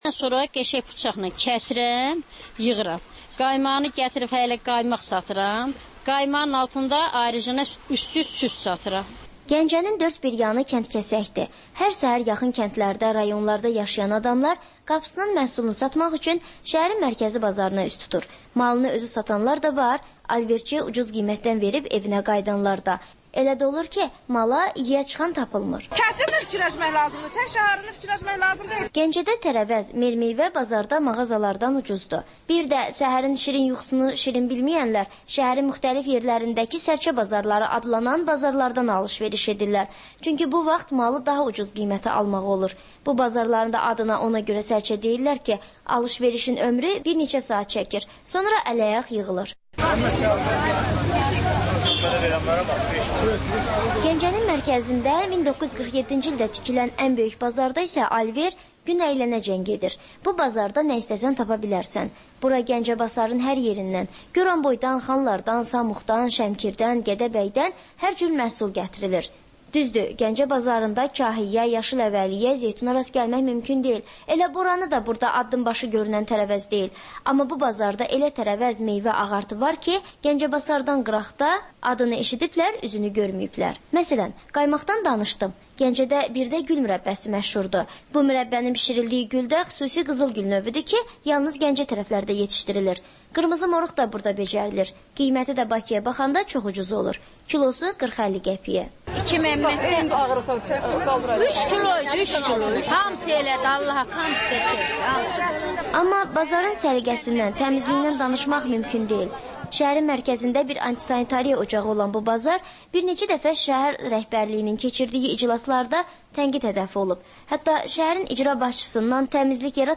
Azərbaycan Şəkilləri: Rayonlardan reportajlar.